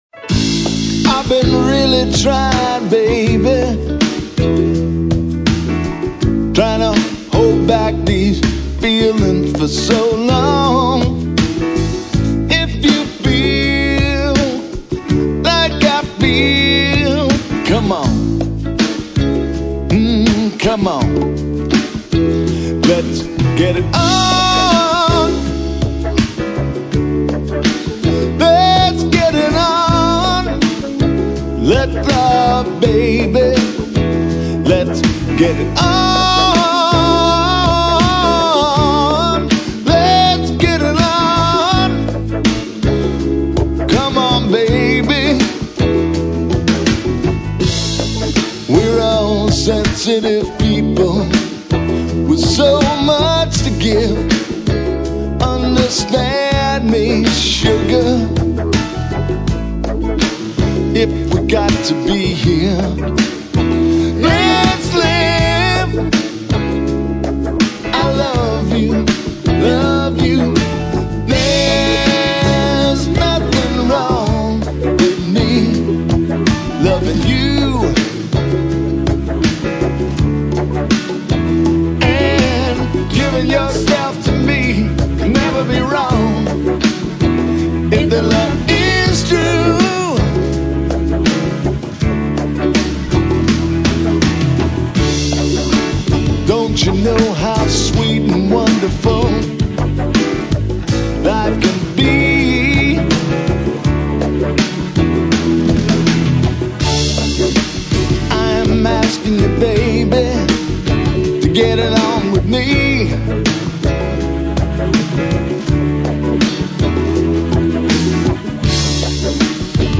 vocal, guitars, strings
piano
bass
drums
background vocals